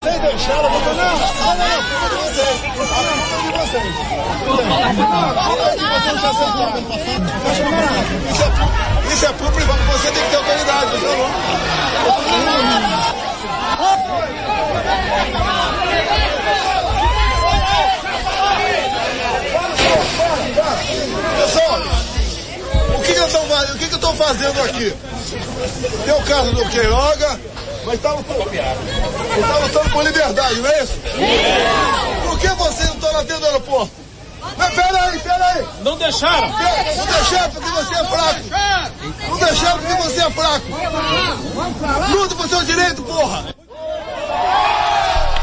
Desde o tumulto na sua chegada ao Aeroporto Castro Pinto, em João Pessoa, até o cancelamento inesperado da entrevista agendada com a imprensa ao meio-dia, alegando uma agenda congestionada.
O momento da fala foi capturado pela TV Arapuan.